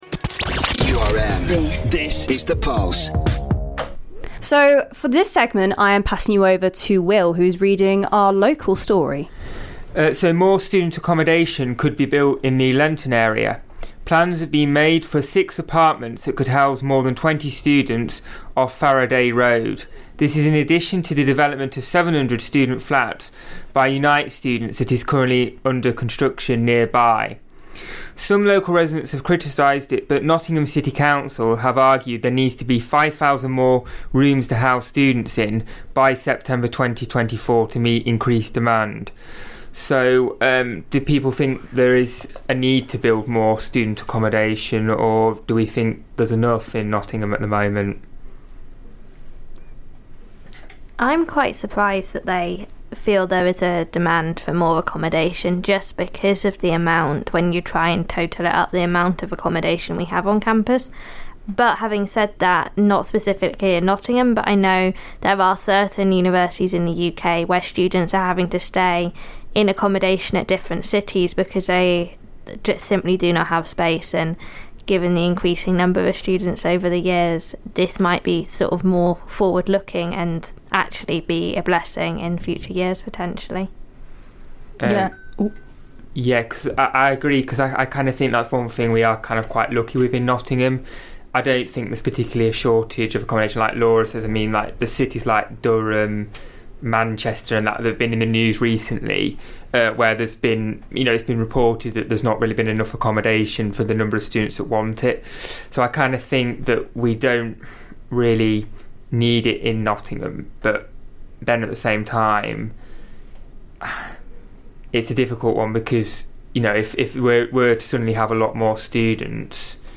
In this highlight of the Pulse, the panel discuss the local backlash to further plans to build student accomodation in Lenton.